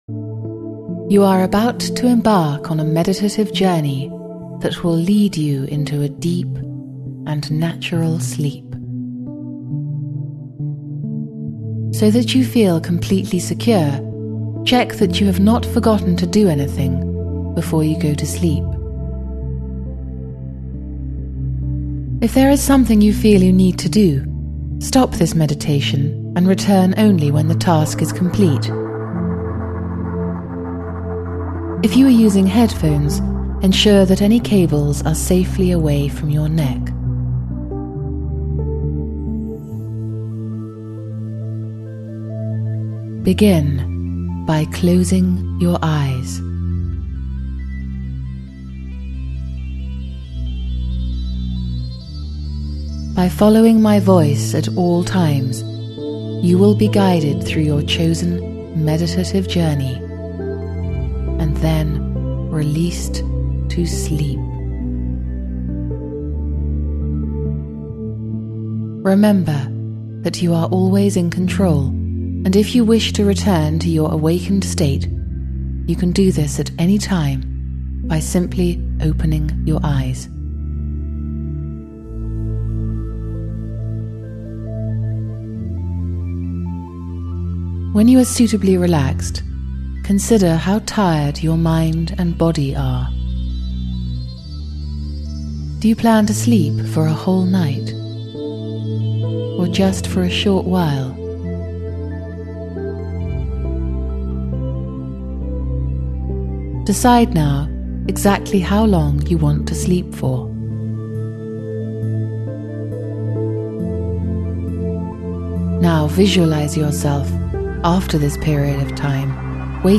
If you’re having trouble sleeping, we’d also recommend trying a guided meditation, such as this one from the Meditainment app by our partner Wellmind Health.